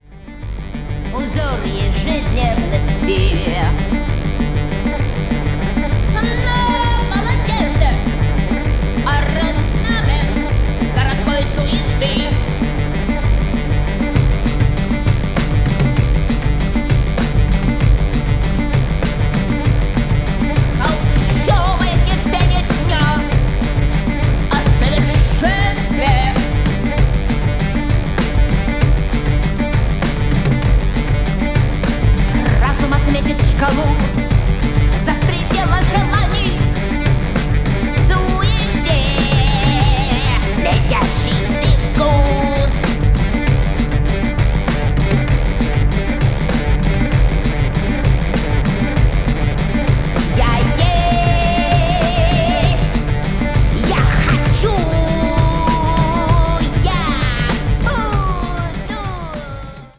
from the bullroarer to sewing machines an d self made organs
throat singer